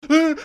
Play, download and share HUUU original sound button!!!!